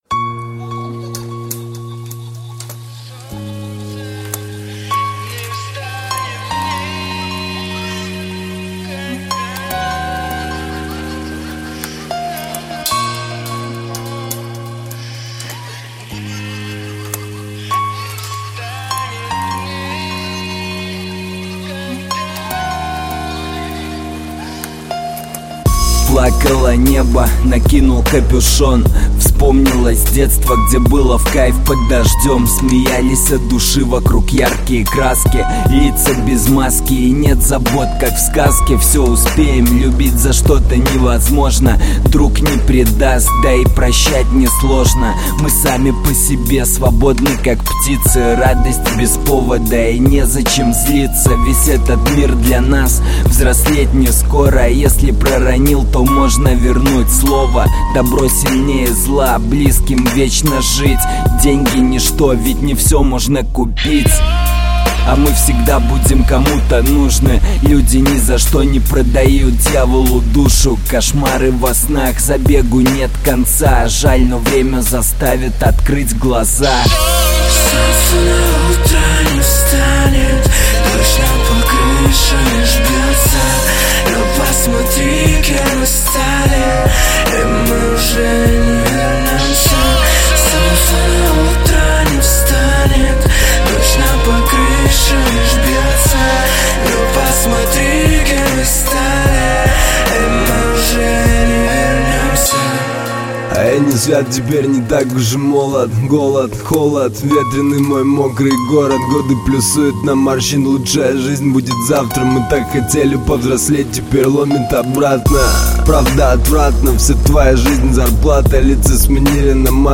Жанр: Хип-хоп / Русский рэп